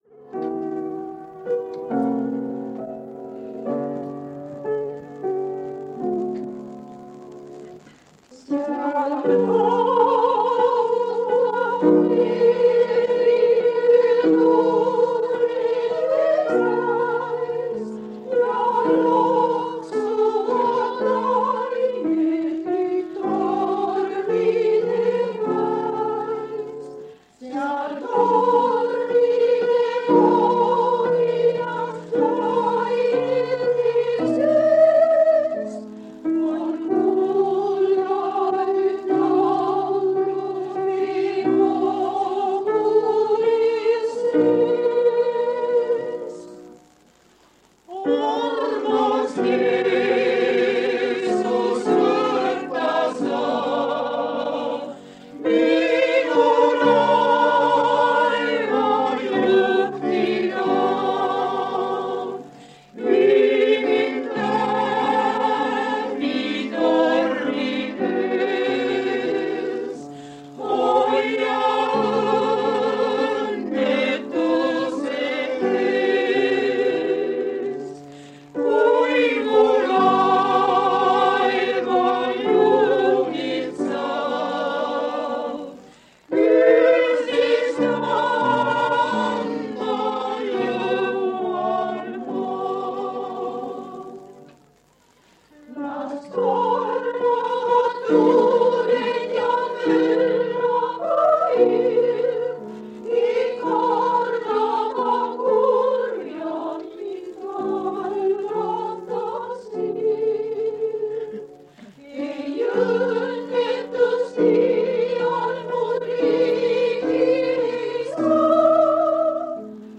Jutlused
On aasta 1976 Türil. Kogudusel on taas aastapäev ja külas mitmeid jutlustajaid.